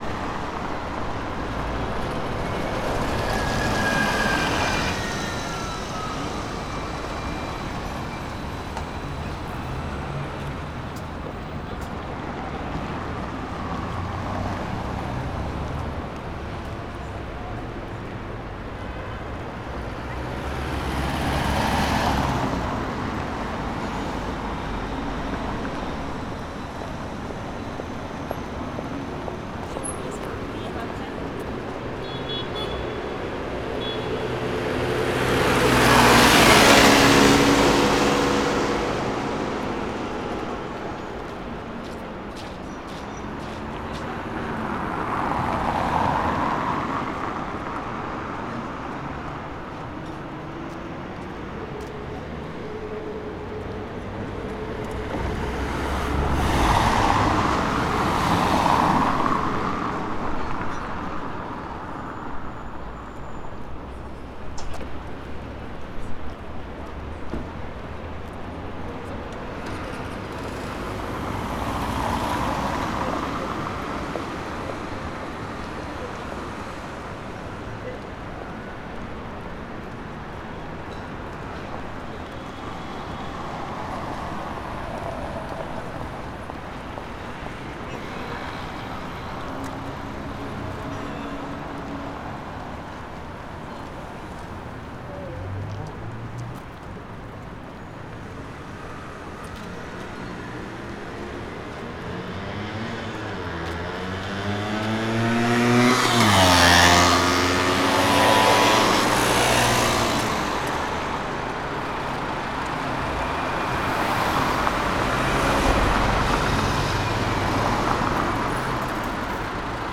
Paris_stret_large2.R.wav